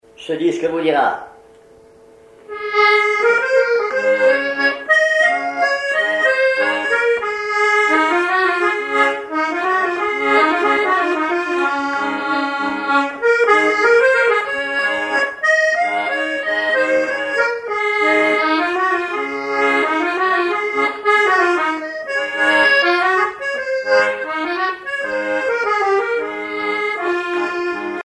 Mémoires et Patrimoines vivants - RaddO est une base de données d'archives iconographiques et sonores.
accordéon(s), accordéoniste
danse : valse musette
Répertoire à l'accordéon chromatique
Pièce musicale inédite